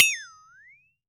FLEXATONE  2.WAV